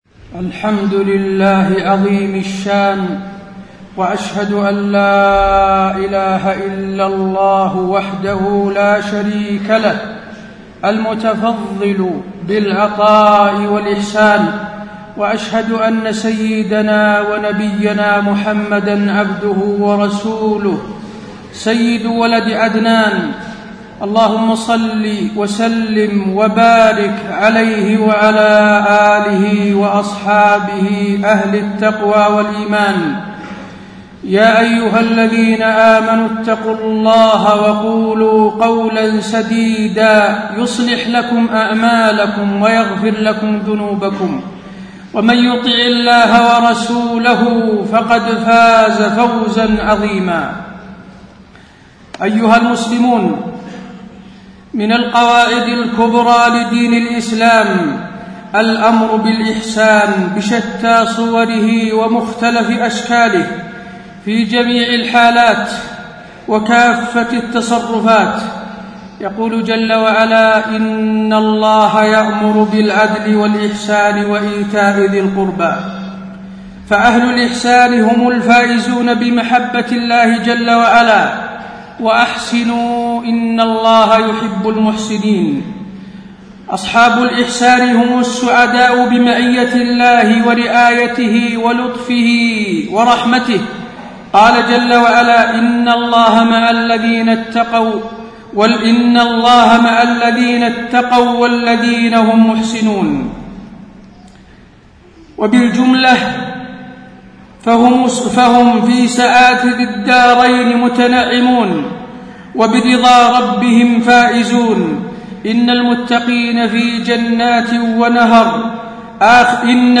تاريخ النشر ٢٨ جمادى الأولى ١٤٣٣ هـ المكان: المسجد النبوي الشيخ: فضيلة الشيخ د. حسين بن عبدالعزيز آل الشيخ فضيلة الشيخ د. حسين بن عبدالعزيز آل الشيخ الأمر بالإحسان